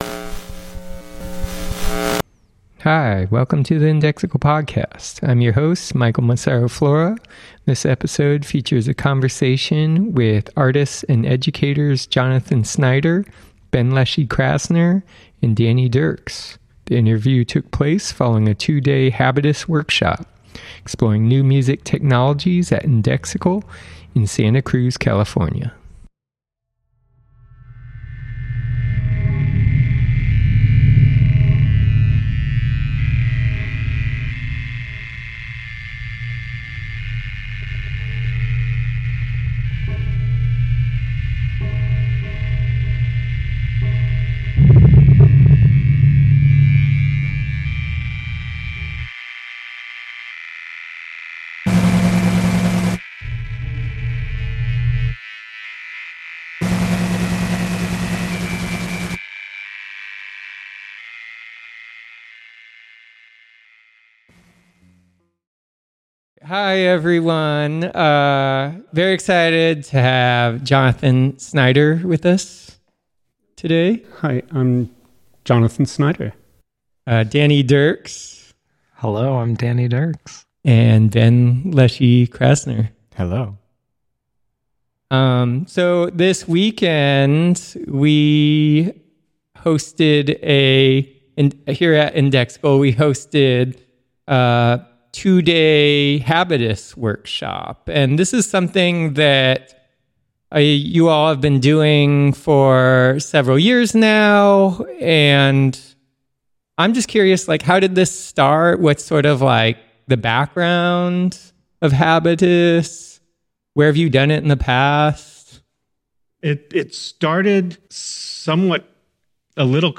Indexical is pleased to present a conversation with artist / educators